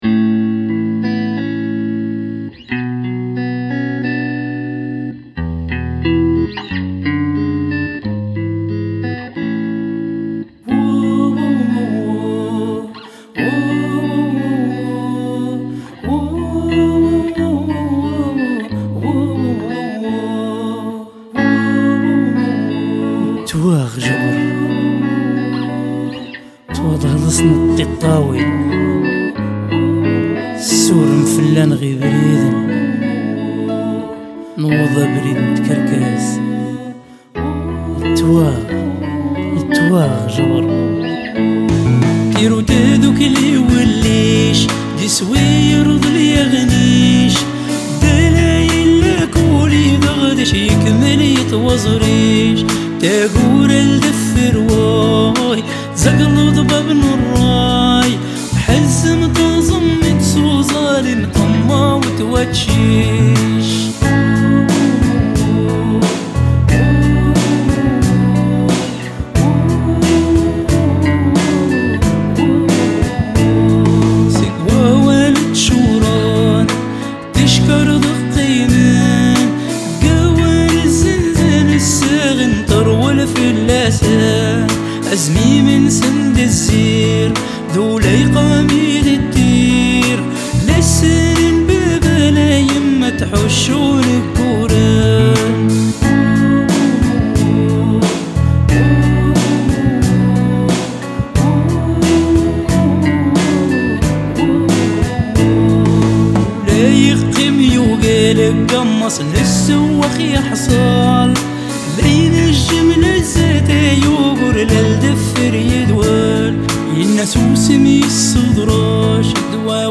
Un correspondant de la chaîne arabe Aldjazira, en direct de Tripoli, indique que les manifestants sont entrain de prendre le contrôle de la Ville.